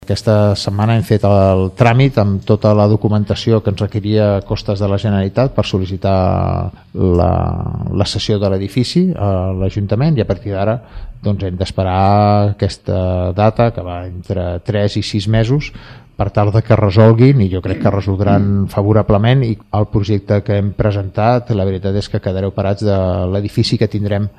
L’alcalde, Carles Motas, ha explicat al programa Línia amb l’Alcalde de Ràdio Sant Feliu que la resposta podria trigar entre tres i sis mesos, però s’ha mostrat confiat en una resolució favorable.